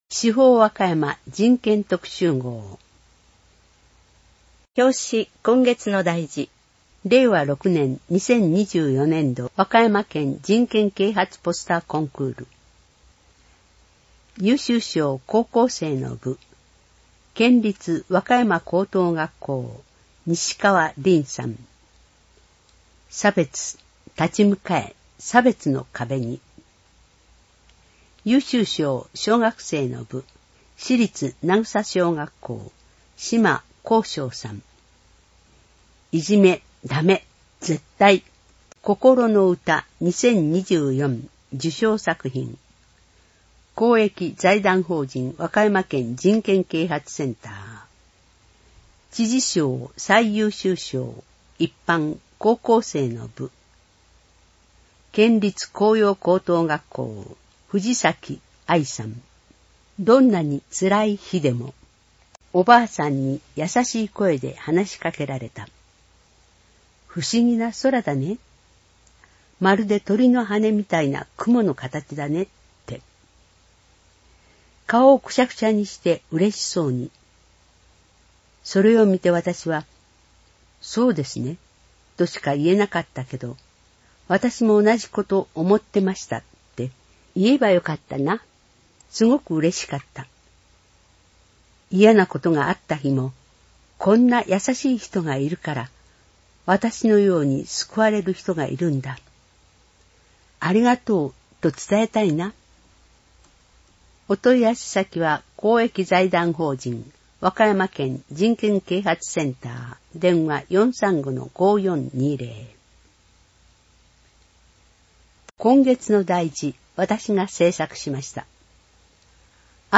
市報わかやま 令和7年人権特集号（声の市報）